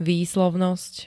Zvukové nahrávky niektorých slov
gnmj-vyslovnost.ogg